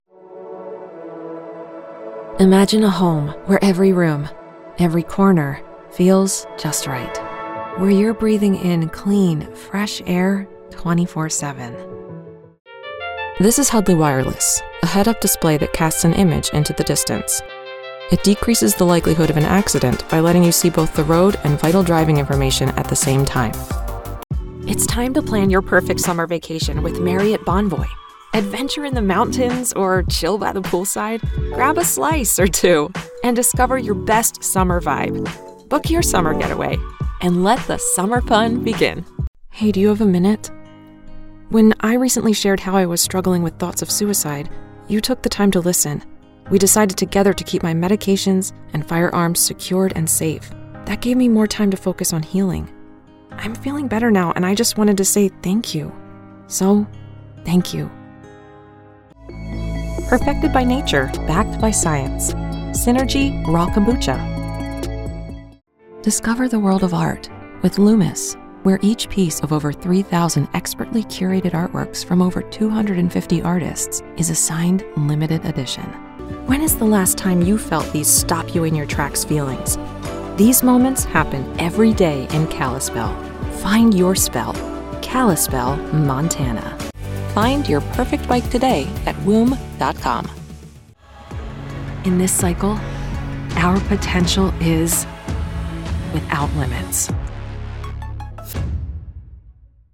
From commercials and explainer videos to e-learning, promos, and narration, I offer a warm, engaging sound designed to connect with your audience.
Commercial Demo